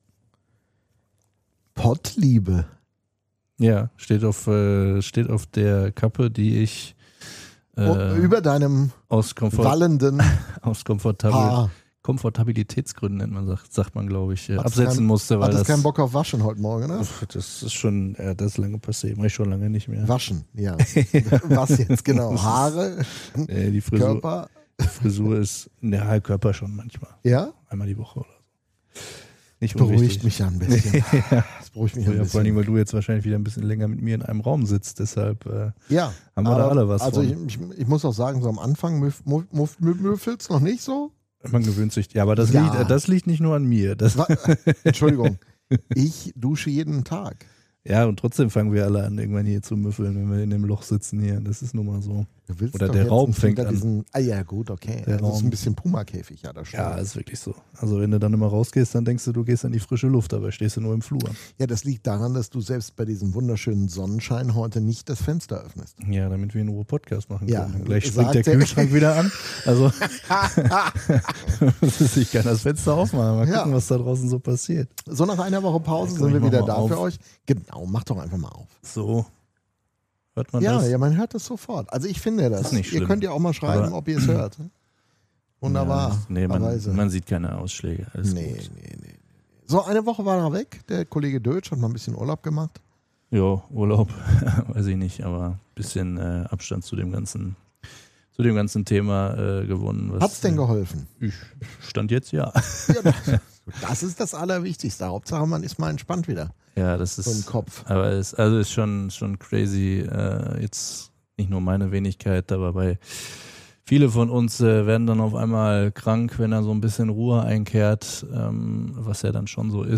Von Triple-Gewinnern und schwierigen Neuzugängen. vor 5 Monaten Im Interview